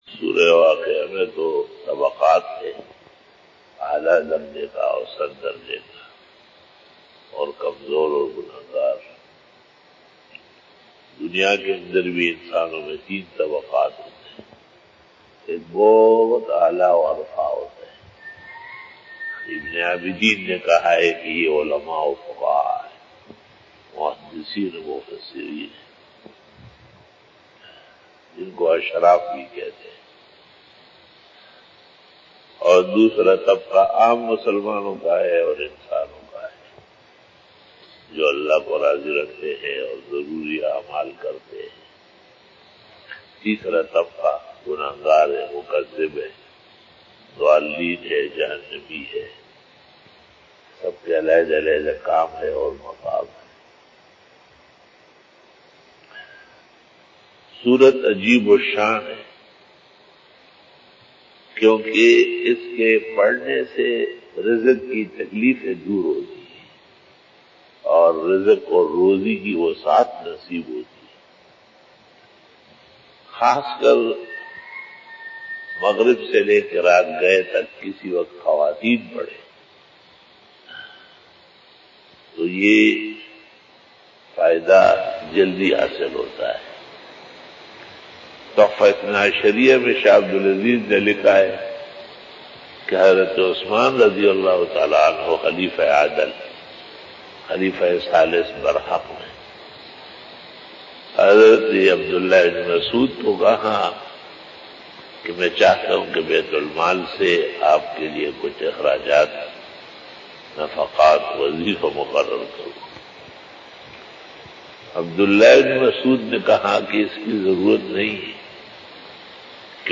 Fajar bayan 18 October 2020 (30 Safar ul Muzaffar 1442HJ) Sunday